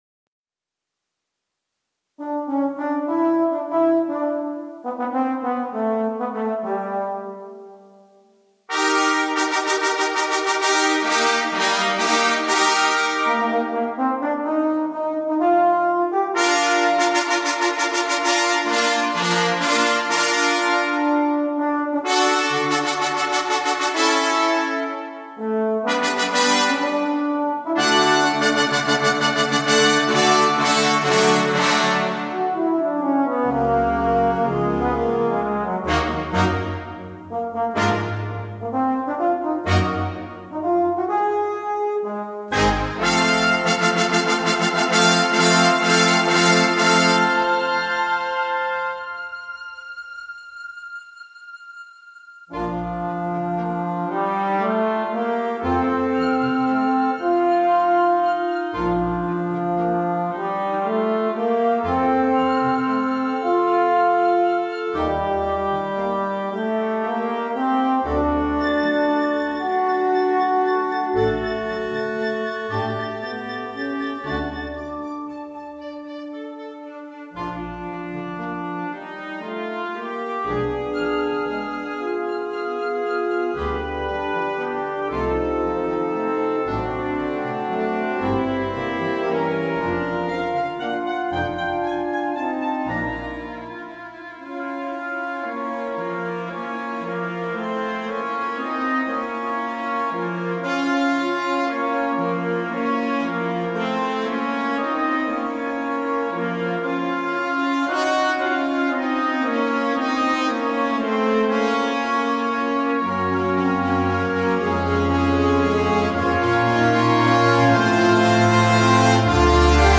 Solo Euphonium and Concert Band
CategoryEuphonium Solo
Solo Euphonium
Flutes 1-2
Bb Trumpets 1-2-3
Tuba
Timpani
Glockenspiel